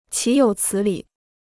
岂有此理 (qǐ yǒu cǐ lǐ) Free Chinese Dictionary